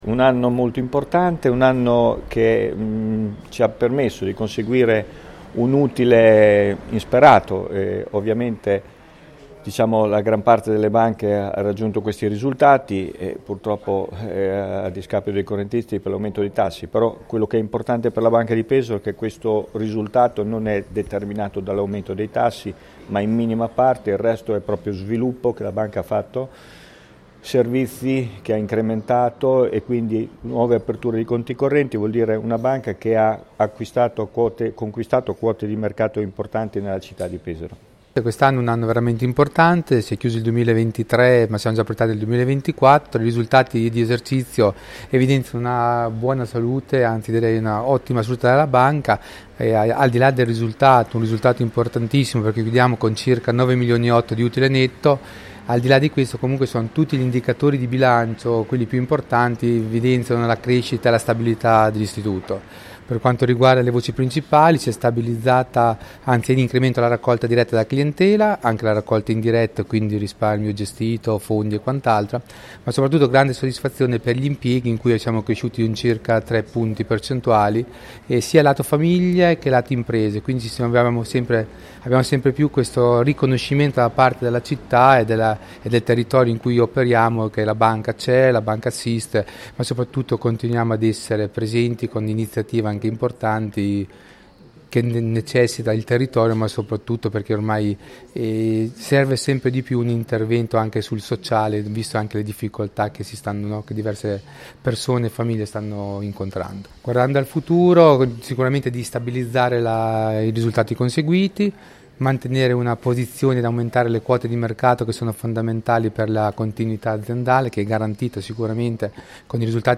Incontro con la stampa al Polo Pasta & Pizza, con i vertici della Banca di Pesaro che hanno confermato il trend positivo dell’istituto di credito, confermandosi come banca del territorio, vicina alle imprese, alle famiglie e al sociale, con prodotti e servizi dedicati innovativi e sempre più vicini alle esigenze della clientela.